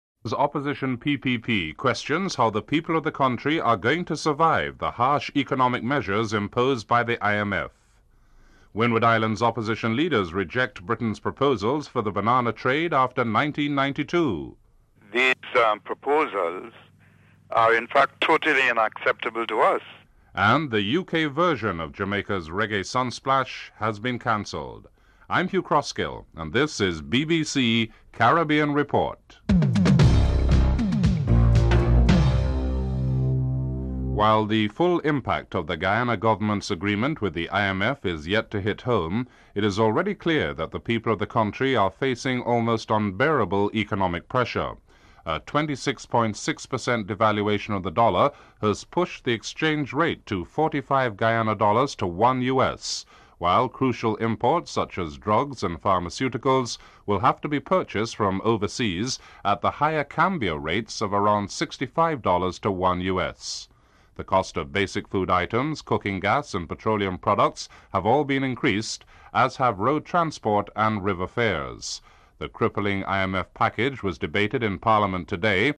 Report commences during the headline news.